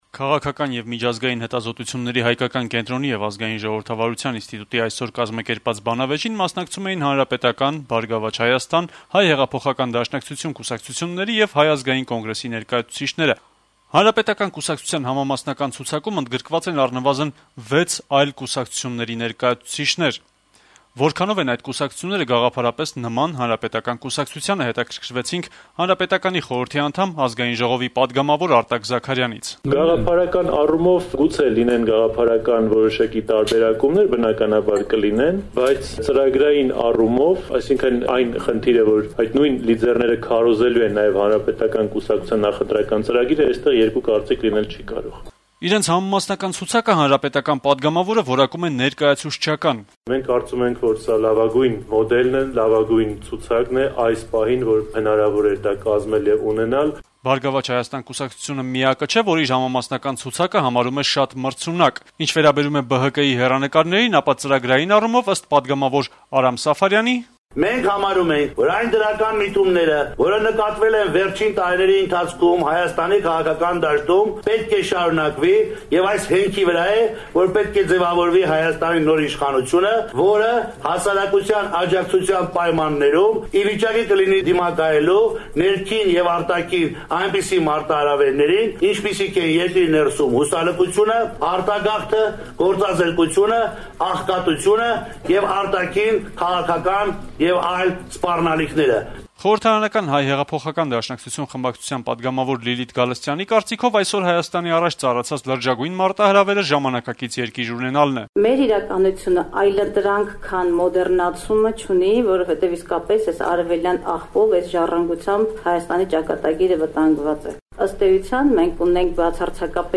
Քաղաքական եւ միջազգային հետազոտությունների հայկական կենտրոնի եւ Ազգային ժողովրդավարության ինստիտուտի այսօր կազմակերպած բանավեճին մասնակցում էին Հանրապետական, «Բարգավաճ Հայաստան», Հայ հեղափոխական դաշնակցություն կուսակցությունների եւ Հայ ազգային կոնգրեսի ներկայացուցիչները: